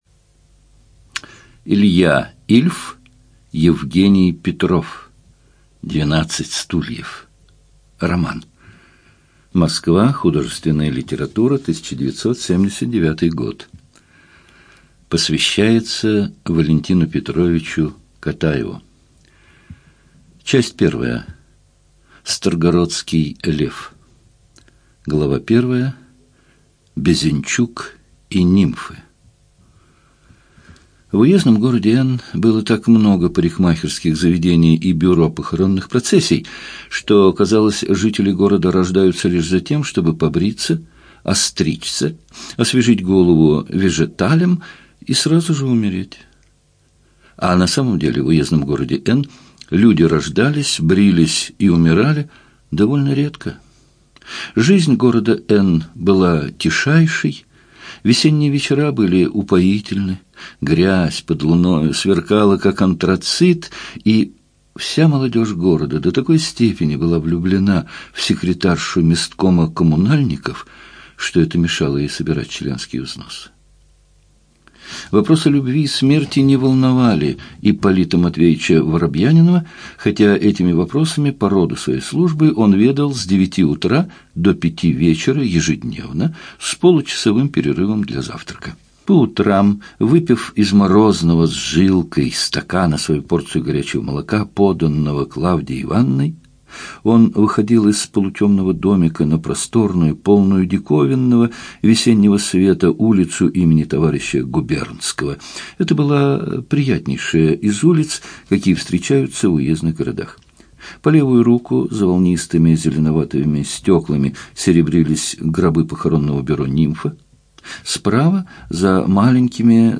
ЖанрЮмор и сатира, Классическая проза
Студия звукозаписиЛогосвос